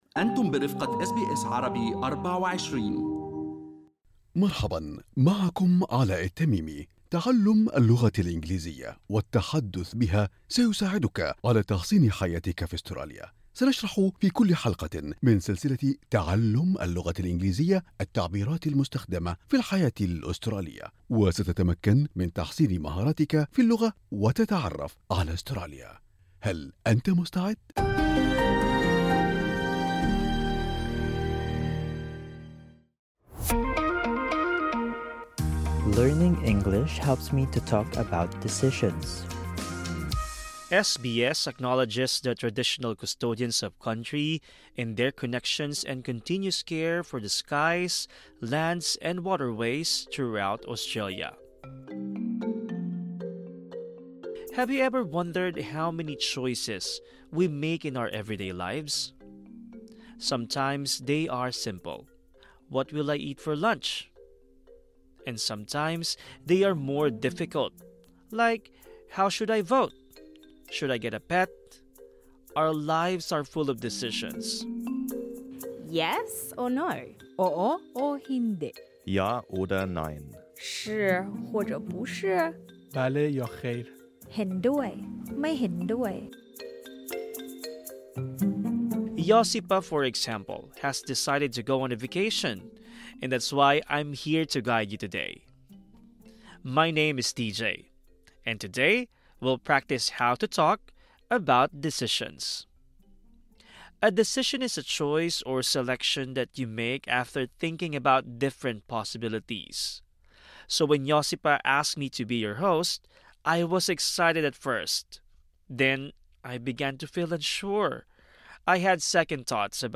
تعلم كيفية التحدث عن القرارات السهلة والصعبة. بالإضافة إلى ذلك، استمع إلى المهاجرين وهم يناقشون قراراتهم بالانتقال إلى أستراليا.
هذا الدرس مفيد للمتعلمين من المستوى المتوسط.